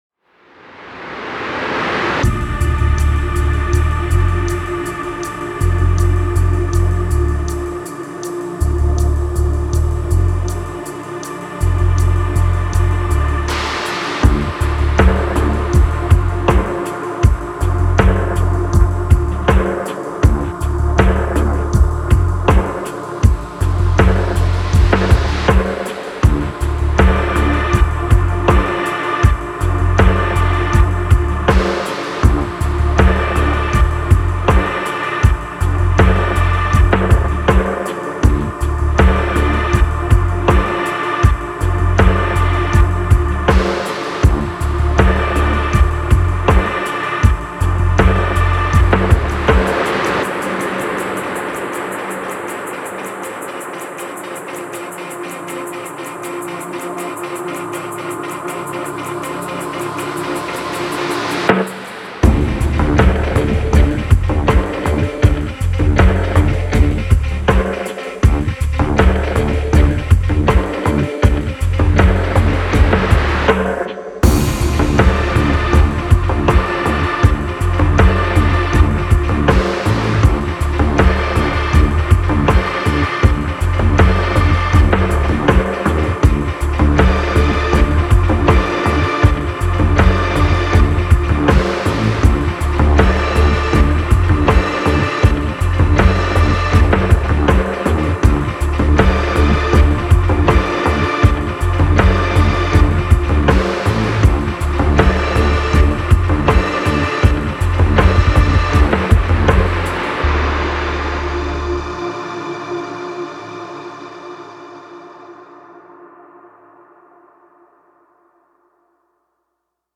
Menacing, gloomy beat with tense shrinning synth lines.